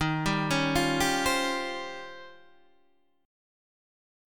Eb13 chord